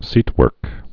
(sētwûrk)